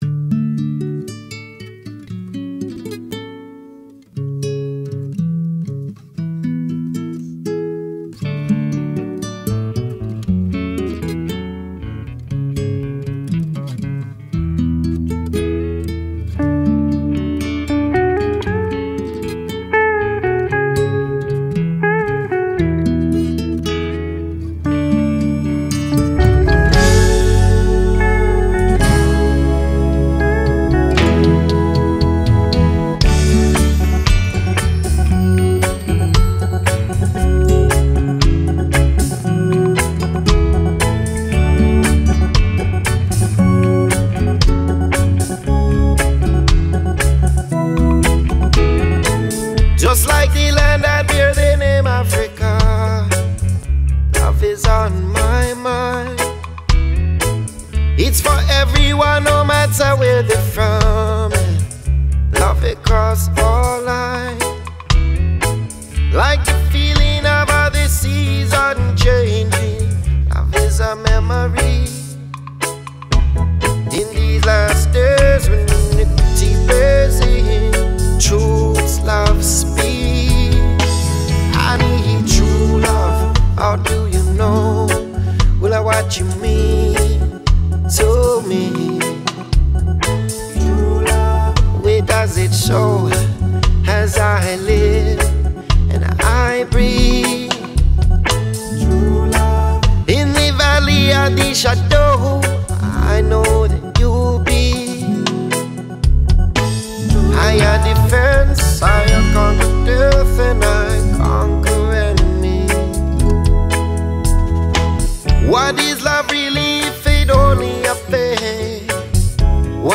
2025-02-13 16:23:31 Gênero: Reggae Views